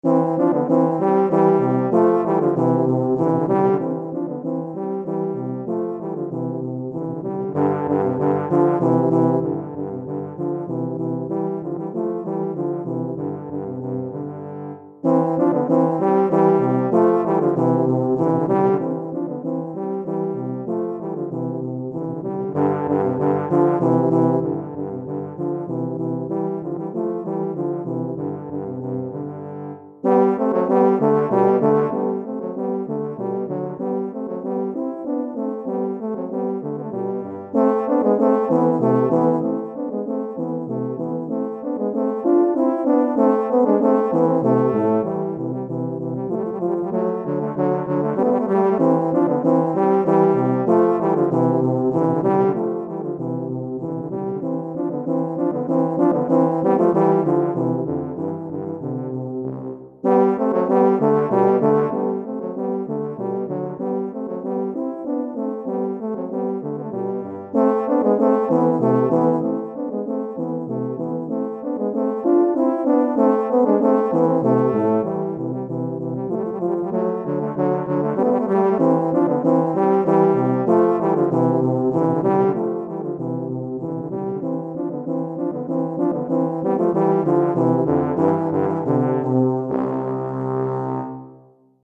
Recueil pour Tuba, euphonium ou saxhorn - 2 Tubas